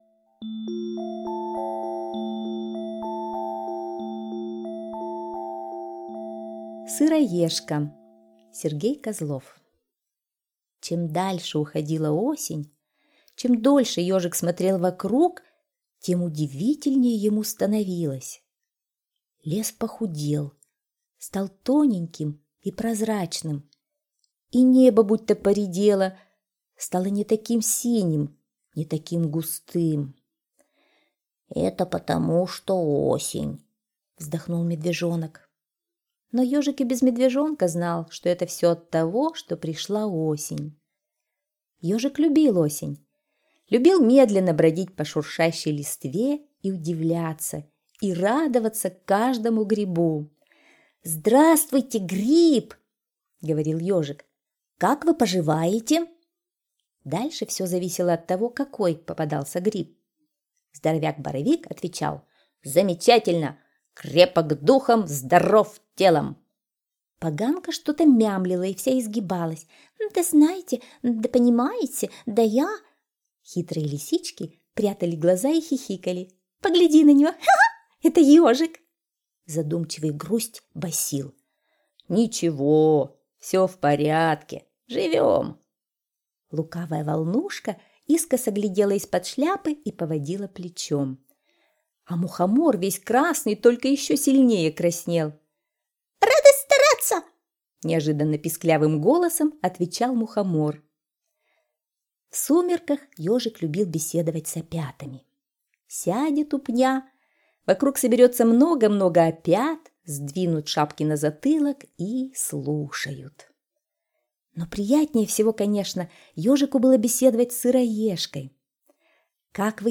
Слушайте Сыроежка - аудиосказка Козлова С.Г. Сказка про Ежика, который осенью любил наблюдать за природой и разговаривать с разными грибами.